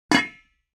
panela.mp3